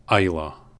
Islay (/ˈlə/
EYE-lə; Scottish Gaelic: Ìle, Scots: Ila) is the southernmost island of the Inner Hebrides of Scotland.